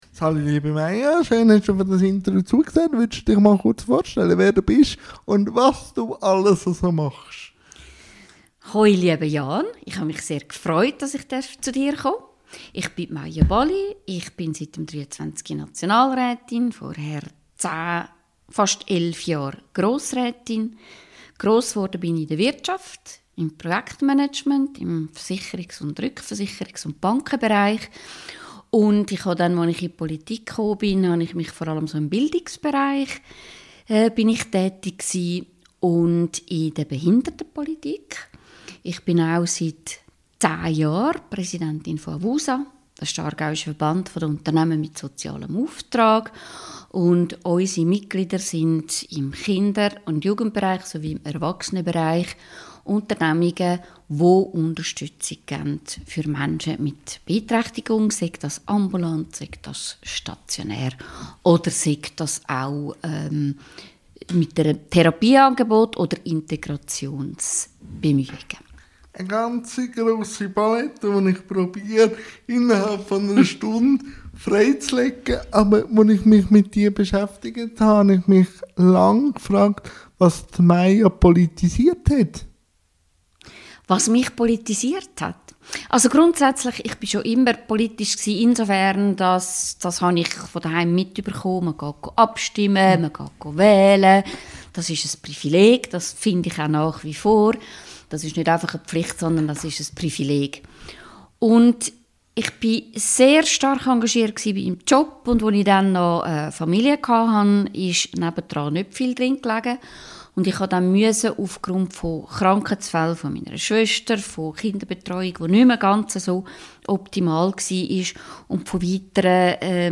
Maya Bally INTERVIEW-THEMEN | Politik, AVUSA u. v. m LINKS